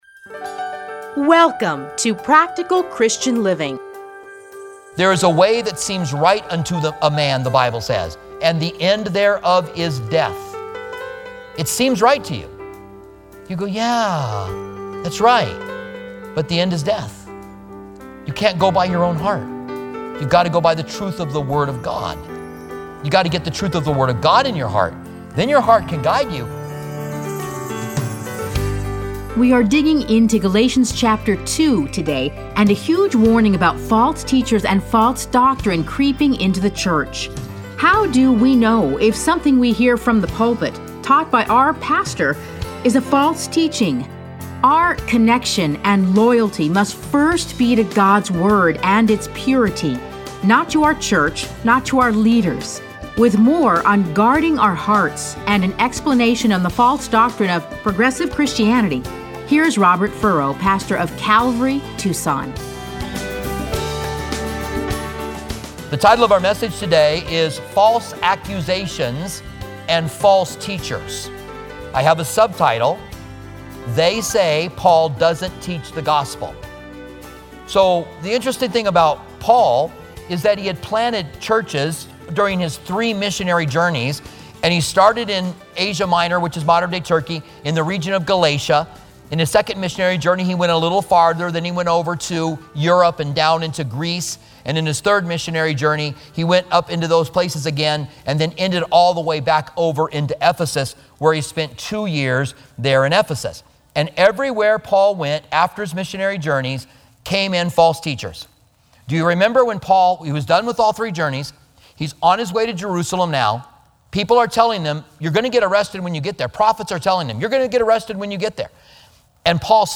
Listen to a teaching from Galatians 2:1-10.